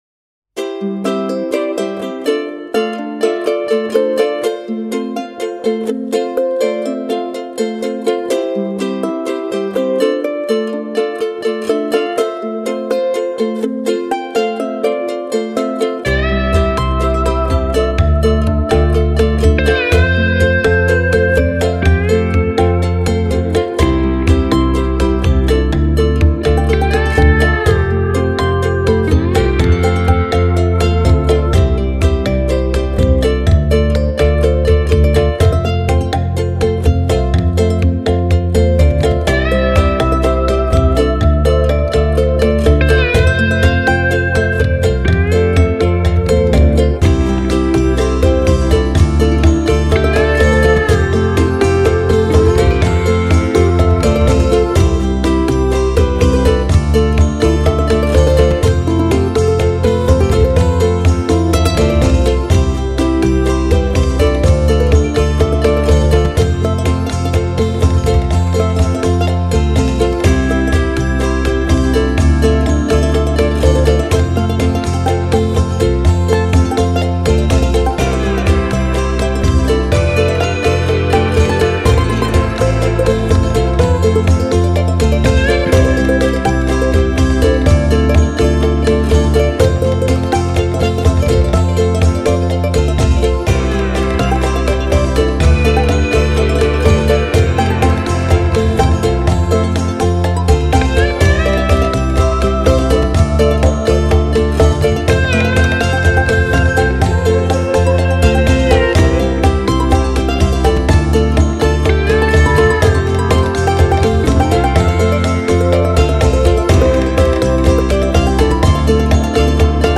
Largo [0-10] joie - ukulele - - -